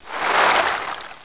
00155_Sound_mer.mp3